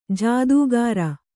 ♪ jādūgāra